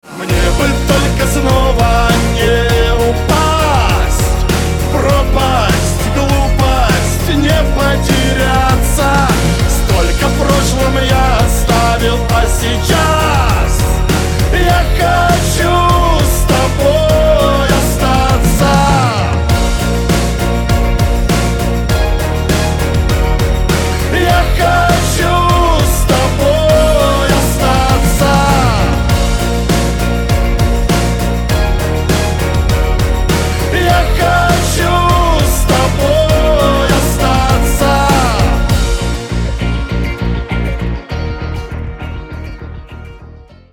• Качество: 256, Stereo
шансон